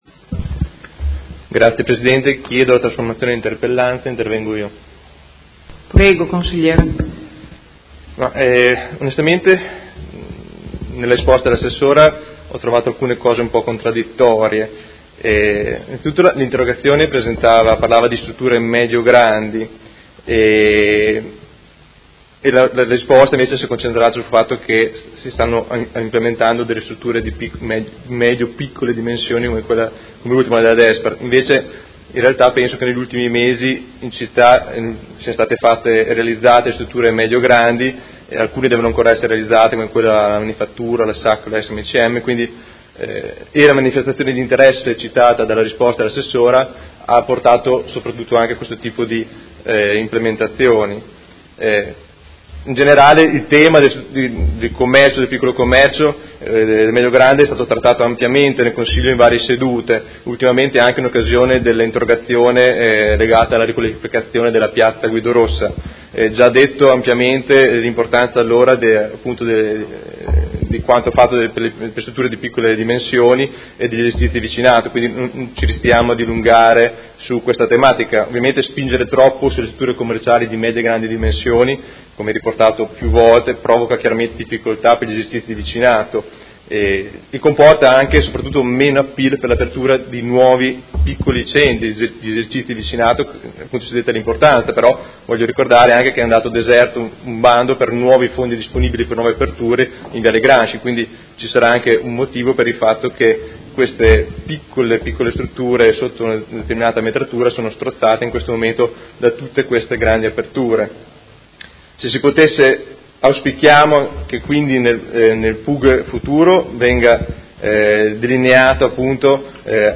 Seduta dell'11/01/2018 Risponde. Interrogazione del Consigliere Morandi (FI) avente per oggetto: Dotare il PUG di strumenti urbanistici atti ad evitare la concentrazione di esercizi di medie e grandi dimensioni.